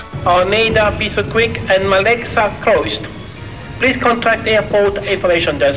Announcers were tricked into saying them under the pretence that they were foreign names.
"We'd go and sit on the balcony at Terminal 3 at Heathrow, directly under one of the speakers as the roof is low.
We put the tape machine in our bag with the microphone poking out of the top.